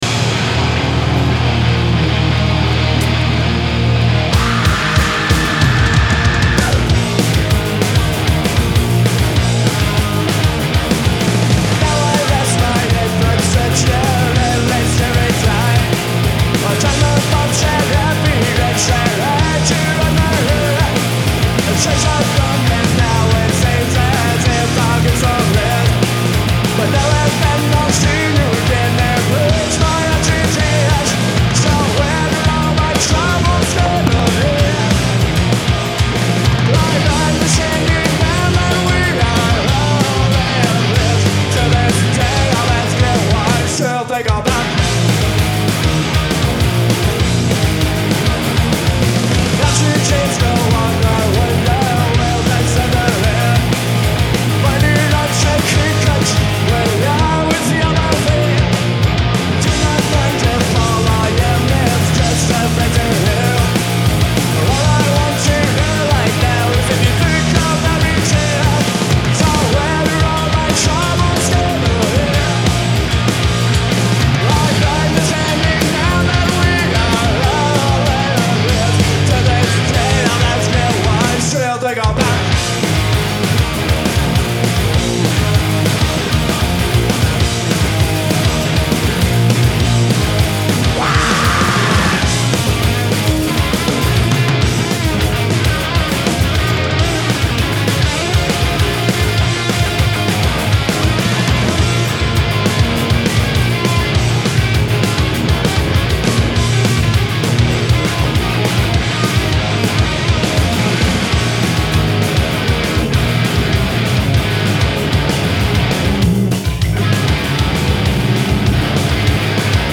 Genre : Alternative & Indie
Live at Woodstock 1994